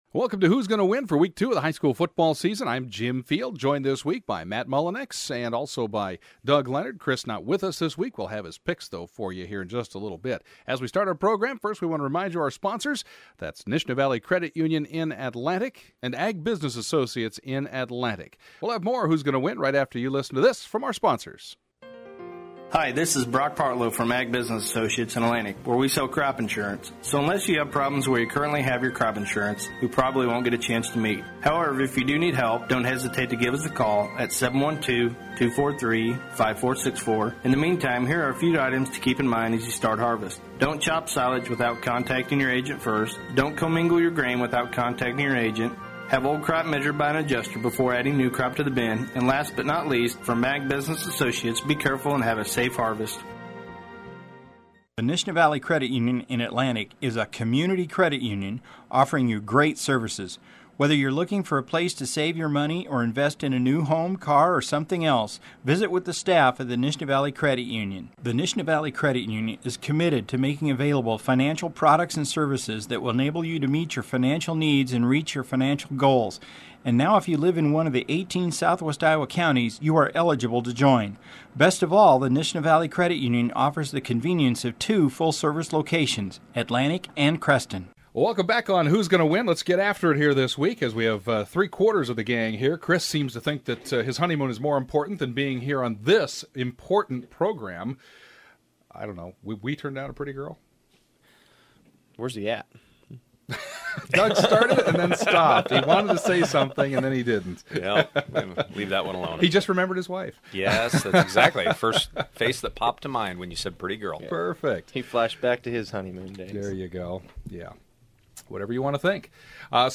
“Who’s Gonna Win?” is a weekly program that airs at 6:00 pm as part of our pre-game coverage of local high school football.